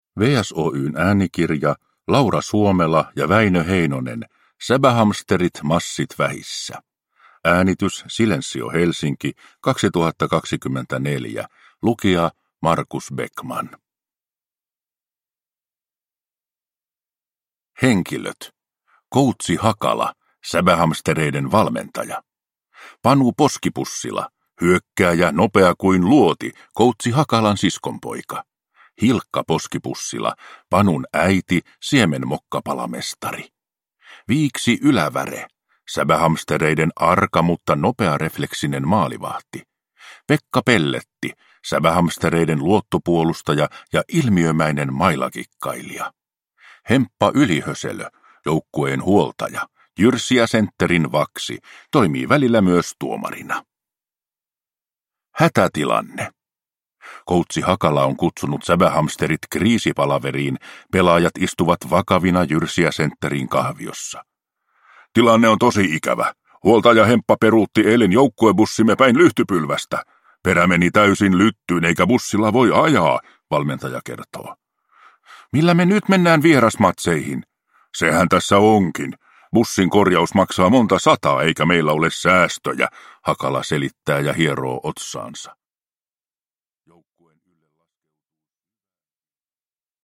Säbähamsterit massit vähissä – Ljudbok